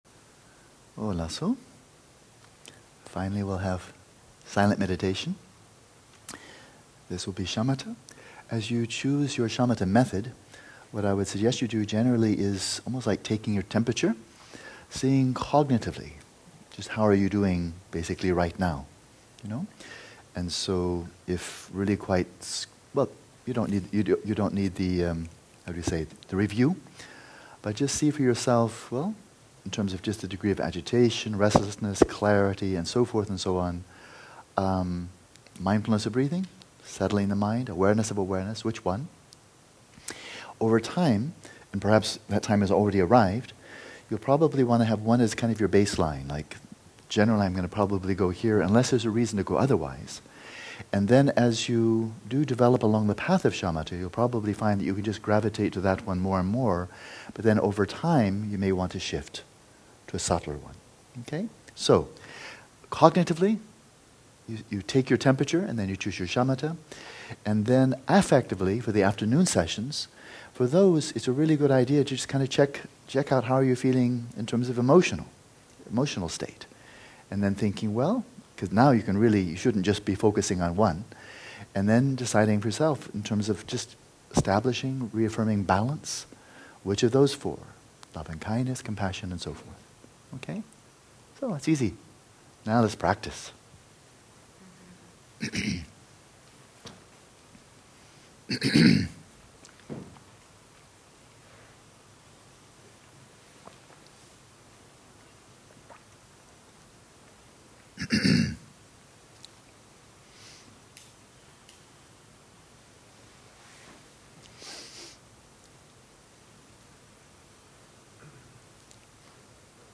This is followed by an unguided 24 minute Gatika.